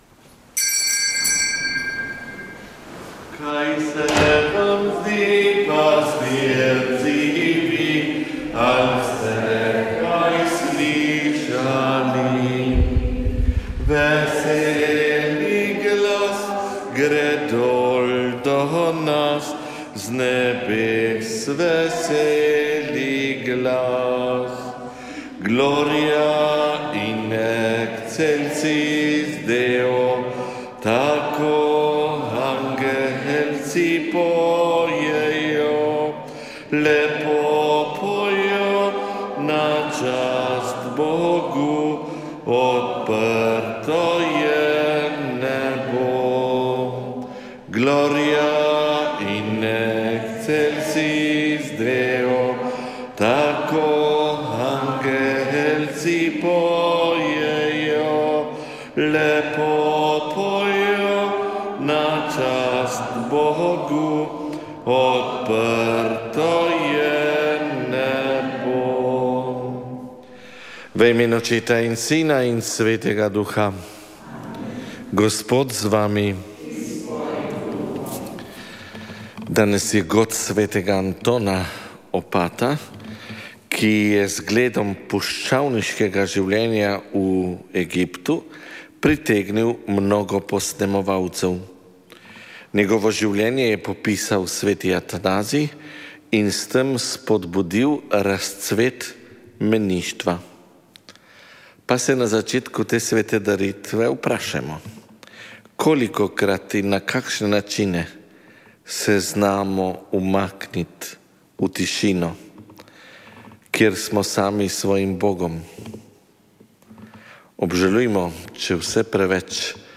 Sv. maša iz stolne cerkve sv. Janeza Krstnika v Mariboru 13. 9.